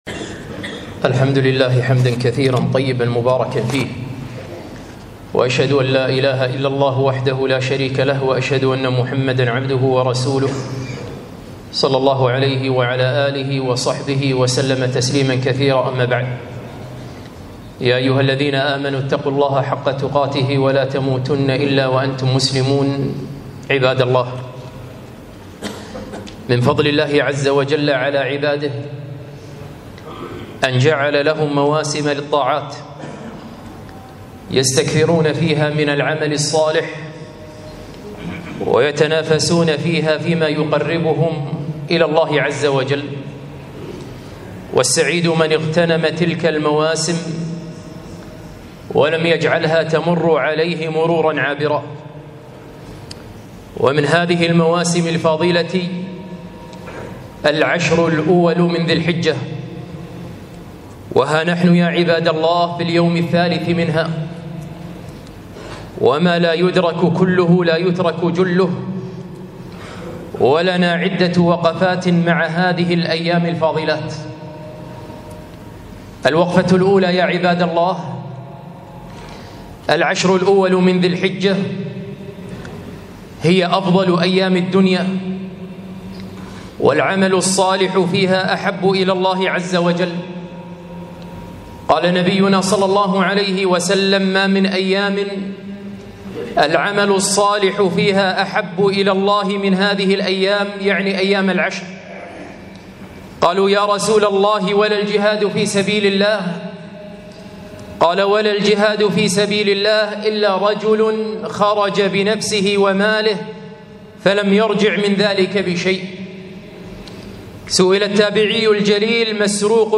خطبة - عشر ذي الحجة